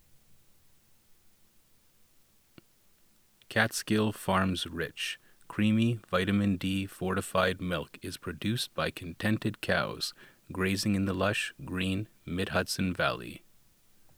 Sound is way off
Comes across as way too loud, robotic, and mechanical.
Followed standard instructions to meet ACX criteria i.e.: effects low-roll-off speech EQ, RMS normalization to -20 dB, limiter to 3.5 soft limit, and noise reduction (based on the noise profile for the first 5 seconds of silence).